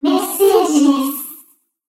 女性ロボットの声で「メッセージです」。